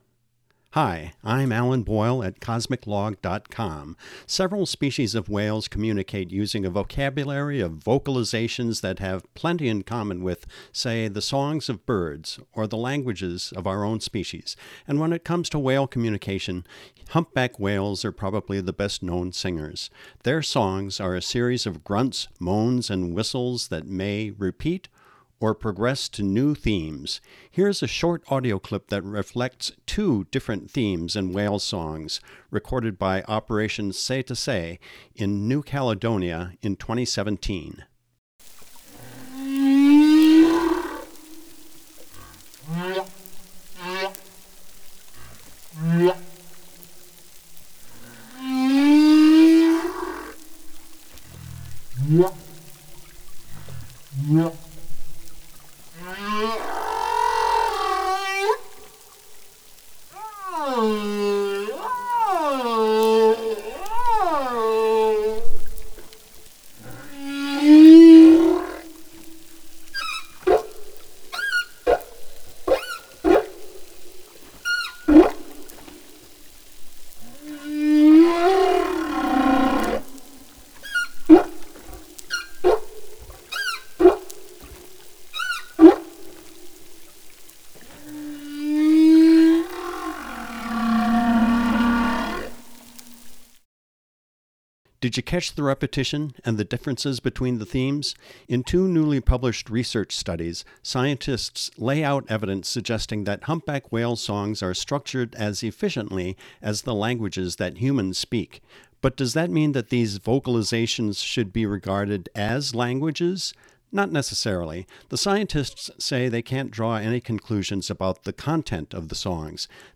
Previous research has suggested that the songs, which are sung exclusively by male humpback whales, may serve to attract mates, fend off challengers and mark territory.
Whale-Songs.mp3